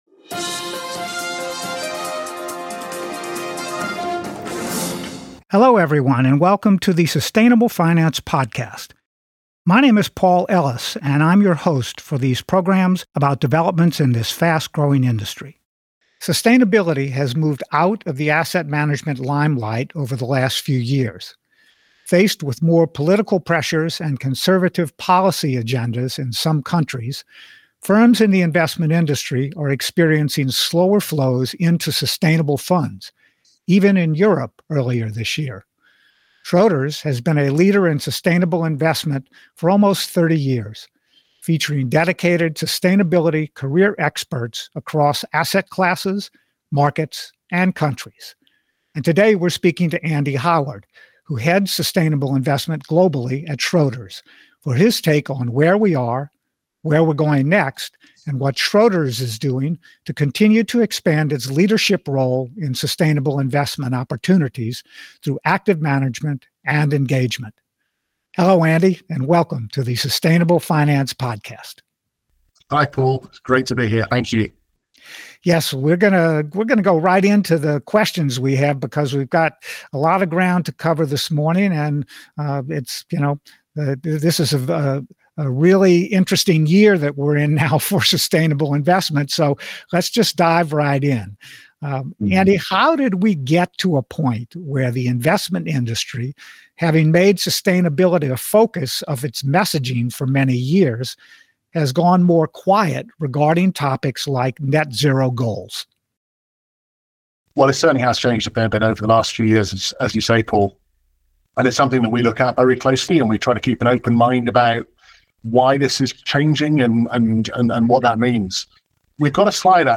interviews leaders in the field